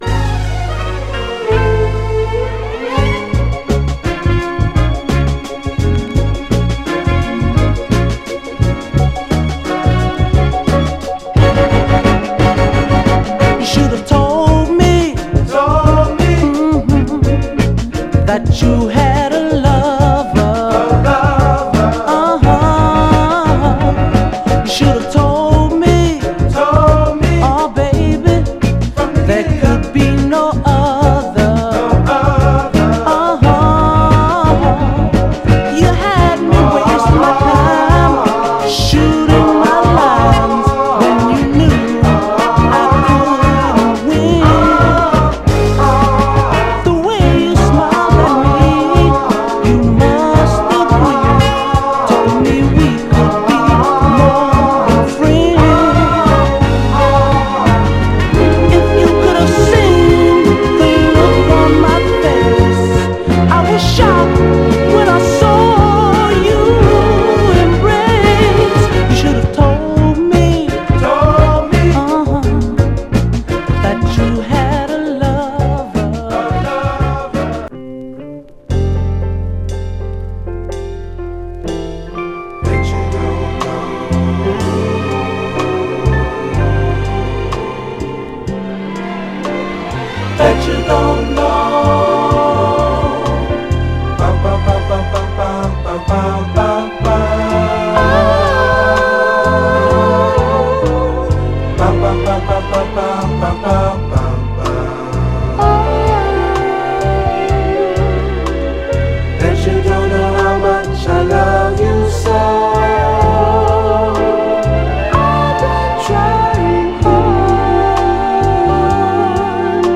美しいメロウ・ソウル
※試聴音源は実際にお送りする商品から録音したものです※